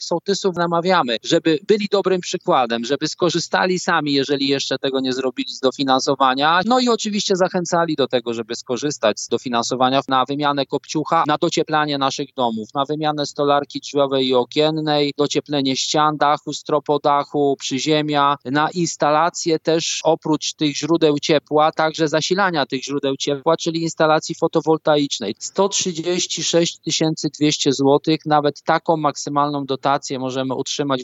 Sołtysi pomogą rozpropagować program Czyste Powietrze jako jego ambasadorzy. – Narodowy Fundusz Ochrony Środowiska liczy, że najlepiej przekonają swych sąsiadów w 40,5 tysiącach sołectw w Polsce do likwidacji „kopciuchów” i termomodernizacji budynków – mówił w Radiu Lublin wiceprezes funduszu Paweł Mirowski: